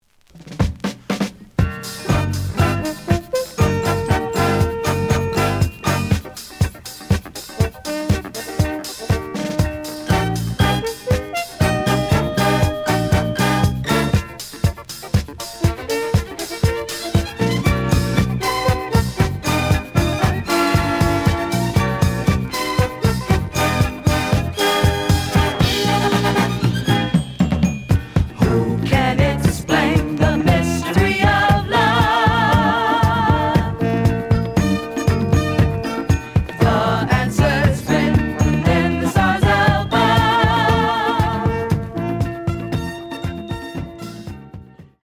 試聴は実際のレコードから録音しています。
●Genre: Disco